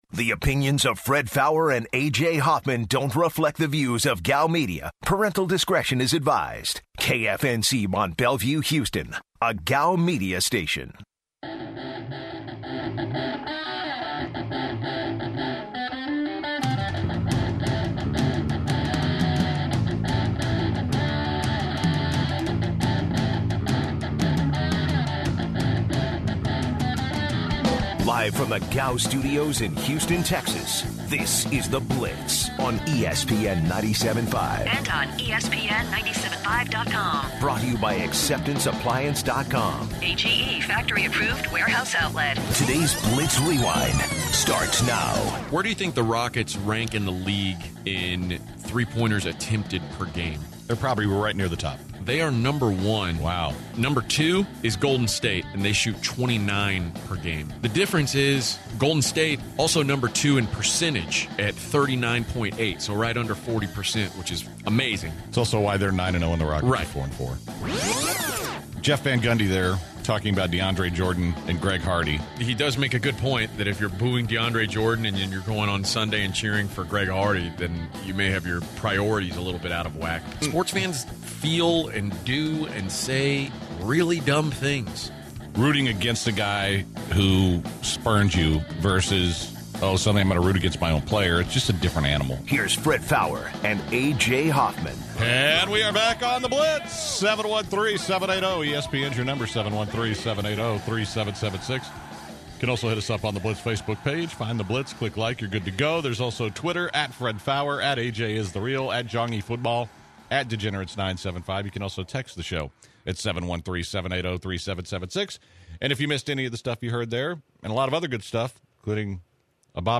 To open the final hour, the guys take calls from Blitzers.